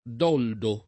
[ d 0 ldo ]